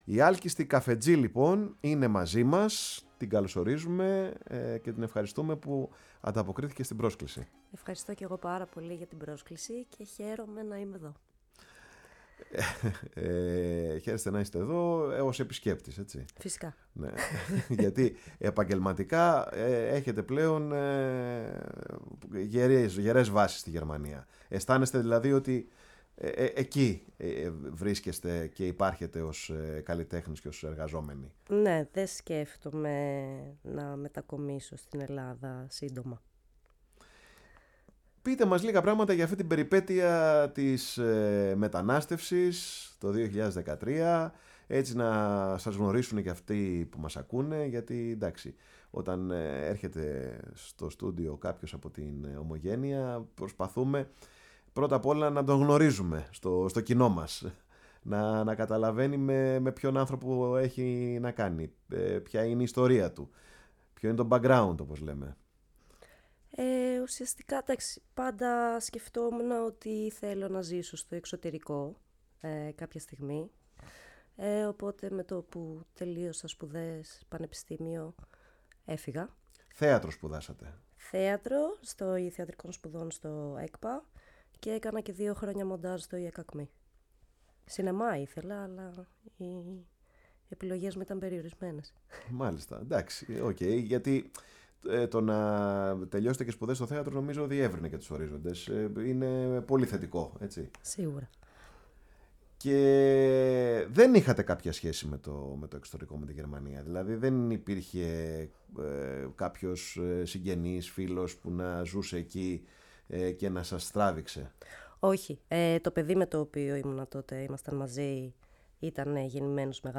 φιλοξένησε σήμερα στο στούντιο, η εκπομπή “Πάρε τον Χρόνο σου”